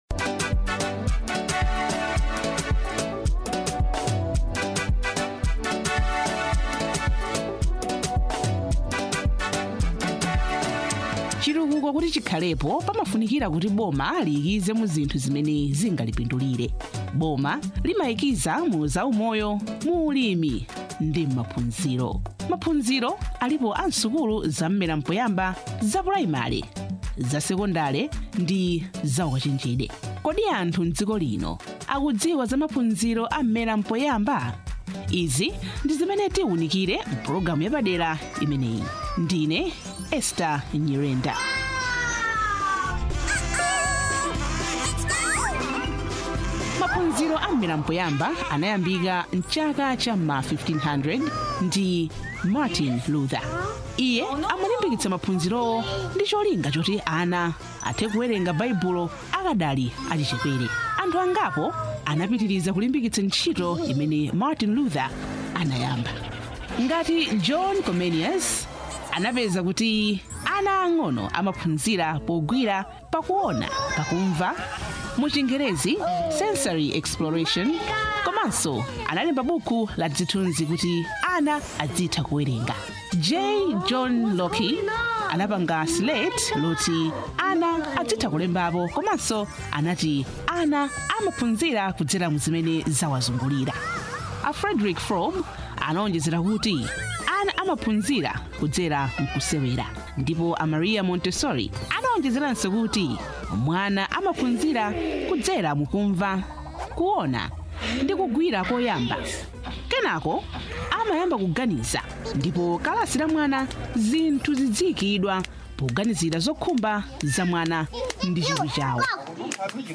DOCUMENTARY ON COMMUNITY AWARENESS ON ECD